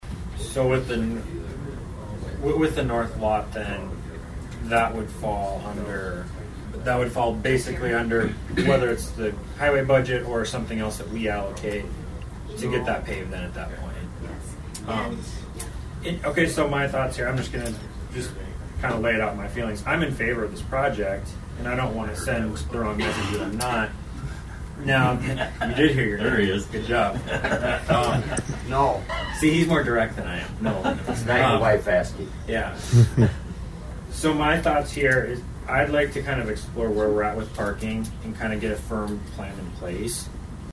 ABERDEEN, S.D.(HubCityRadio)- At Tuesday’s Brown County Commission meeting, the commissioners address a resolution dealing with the possible expansion of Dacotah Prairie Museum.
Commissioner Drew Dennert asked about the parking lot just north of Railroad Avenue.